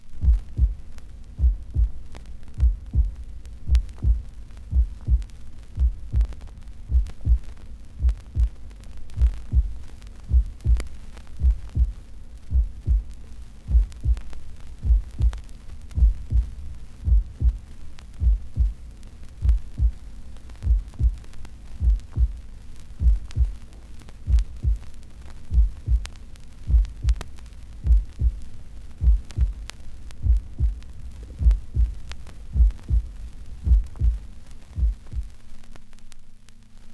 • human heartbeats - vinyl.wav
human_heartbeats_-_vinyl_byx.wav